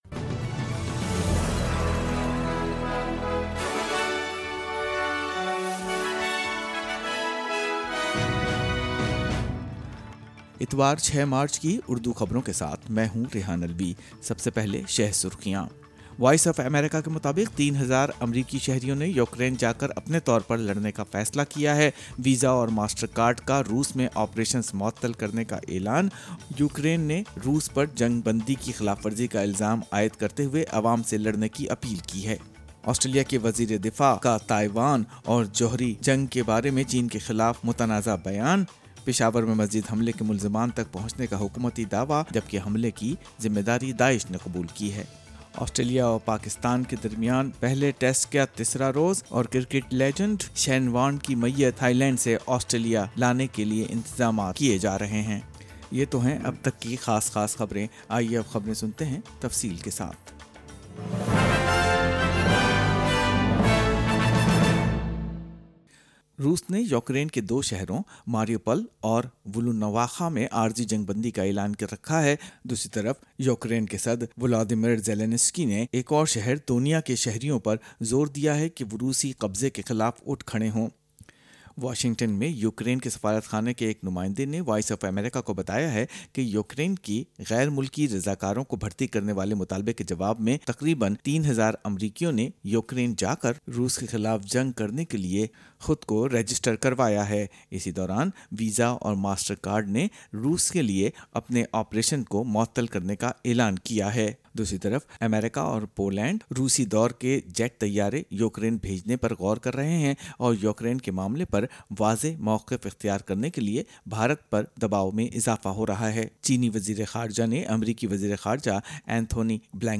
Urdu News 6 March 2022 - More wet weather and floods are expected in New South Wales in the coming days